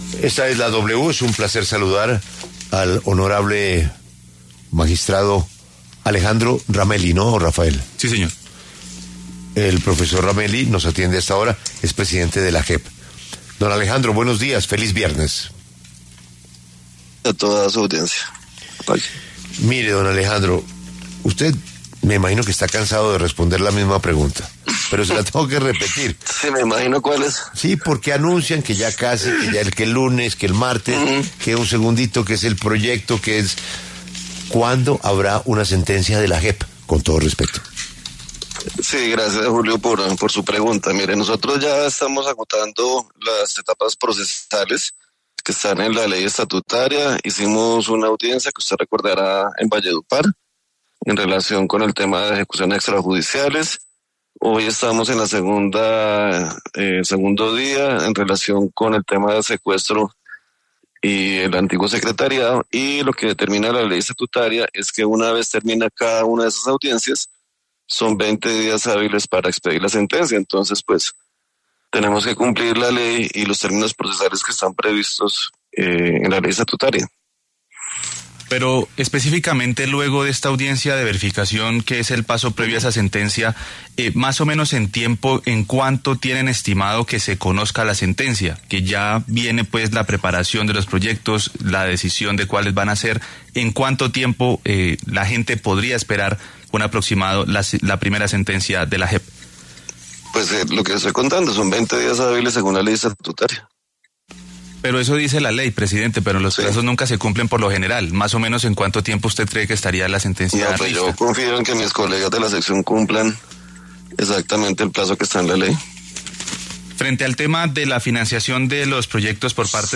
En entrevista con La W, el presidente de la JEP, magistrado Alejandro Ramelli, se refirió a la posibilidad de que el presidente Gustavo Petro y el expresidente Álvaro Uribe puedan ser citados a declarar para que aporten “verdad” como lo ha propuesto el jefe de Estado.